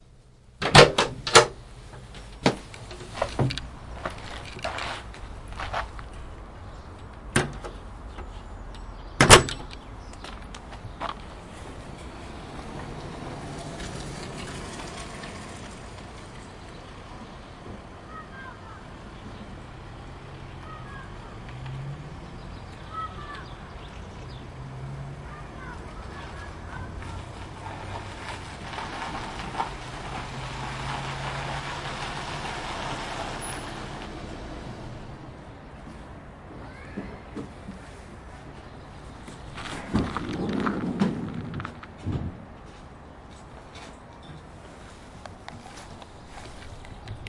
交通工具 " 房子的门打开，从室外出来门关闭汽车来了汽车门
描述：房门打开，户外出口，房门关闭。车到了。
Tag: 现场录音 分机 汽车 INT